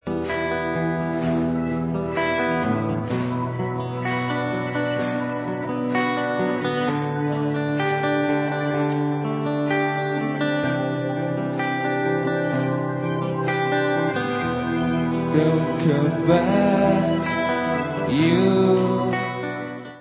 sledovat novinky v kategorii Pop